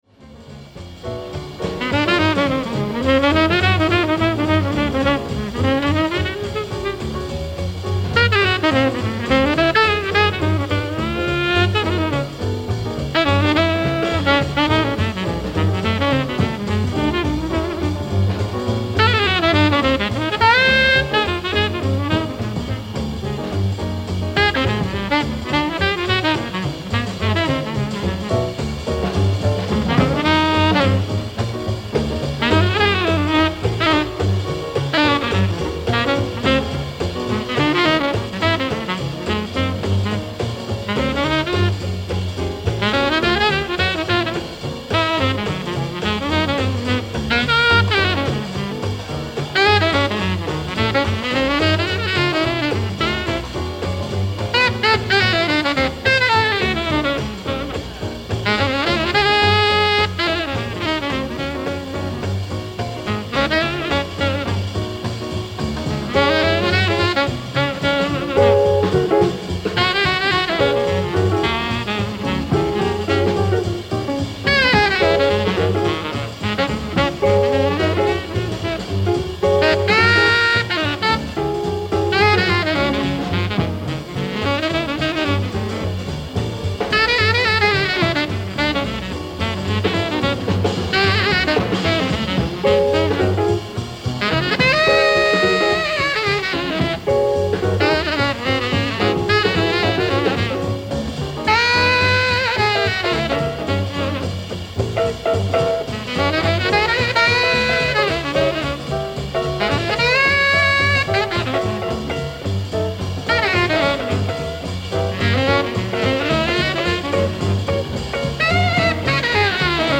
ライブ・アット・ストックホルム、スウェーデン 05/20/1958
※試聴用に実際より音質を落としています。